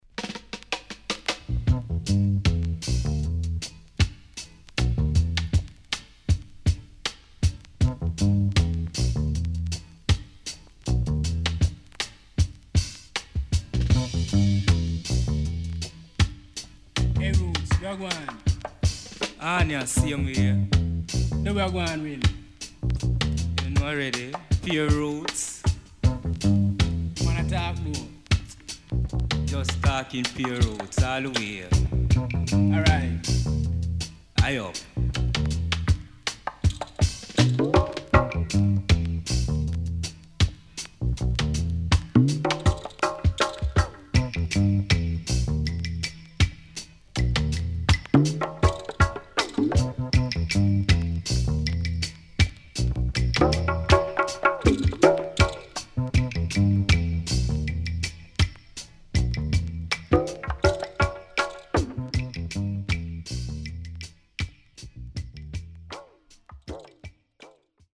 SIDE B:少しノイズ入りますが良好です。